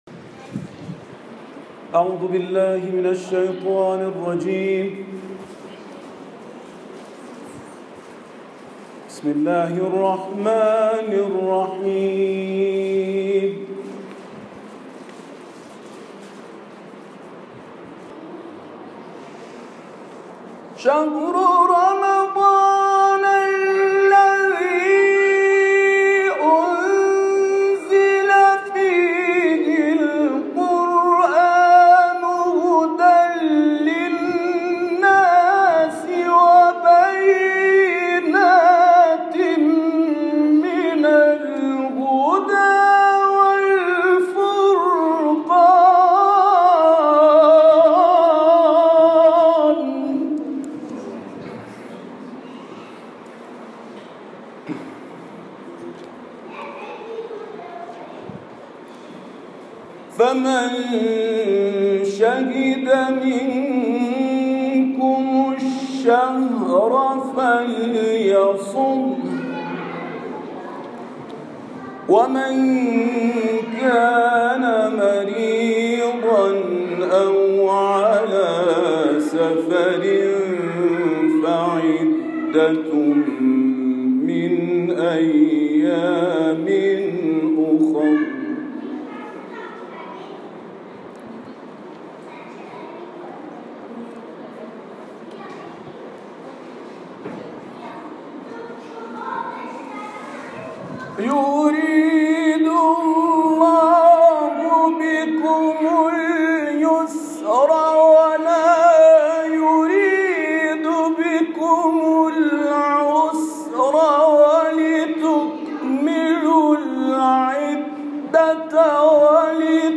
تلاوت آیه 185 سوره مبارکه بقره و سوره مبارکه قدر در عاشوراخانه شيعيان شهر ميسور هند شب 19 ماه مبارک رمضان